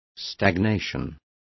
Also find out how estancamiento is pronounced correctly.